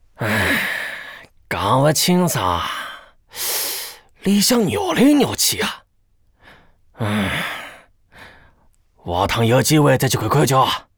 c02_6偷听对话_癞子_2.wav